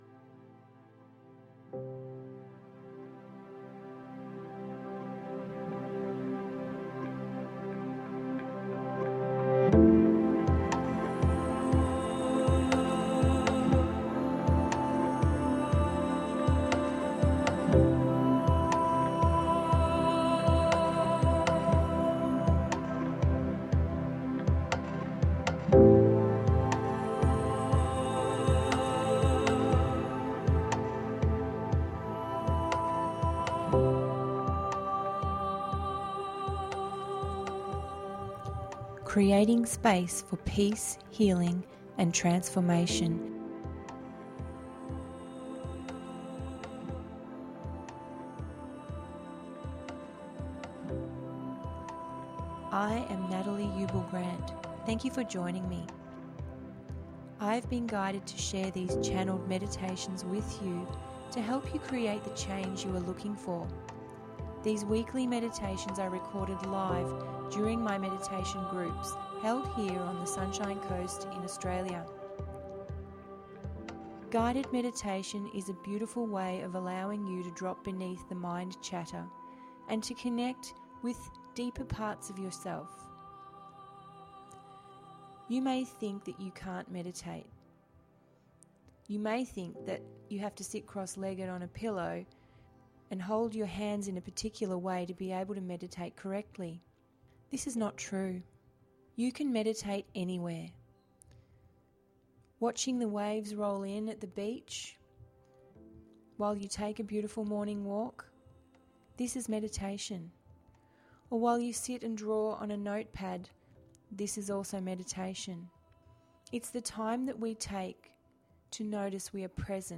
Be given three gifts during this meditation that will have significance for you.